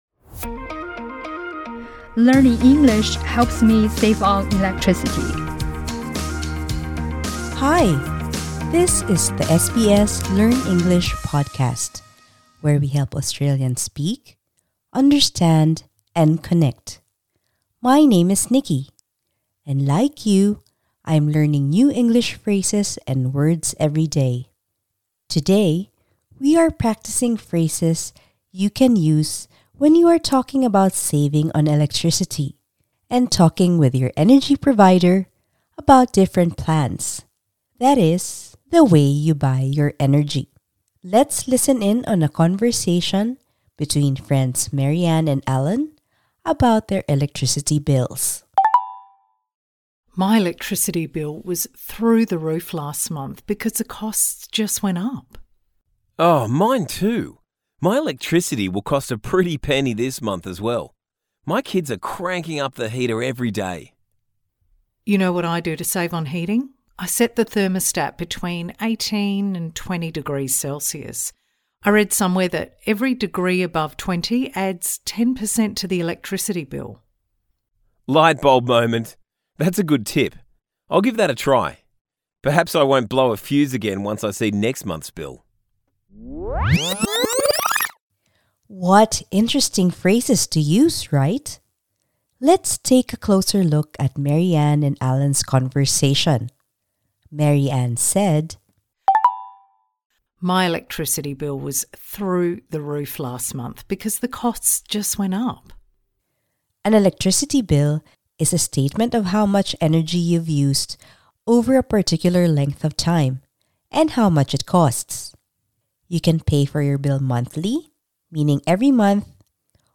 This lesson suits upper-intermediate to advanced learners.